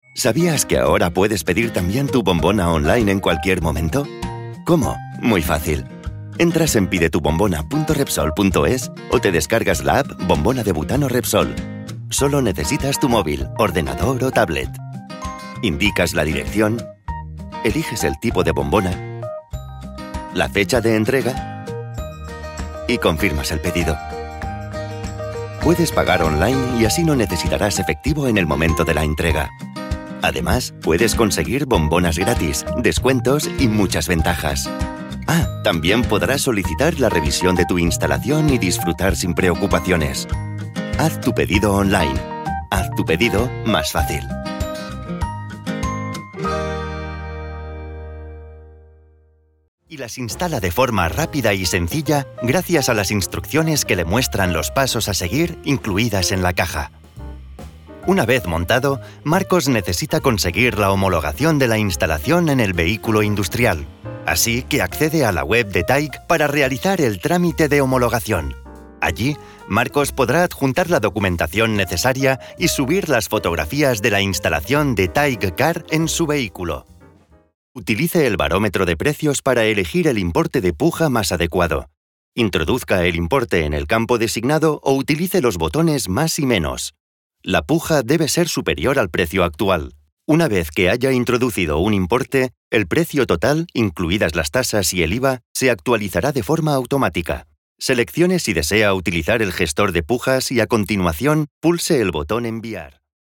Jeune, Accessible, Fiable, Mature, Amicale
Corporate
Sa voix est dynamique et jeune, mais il peut également la moduler vers des tons plus sérieux et chaleureux.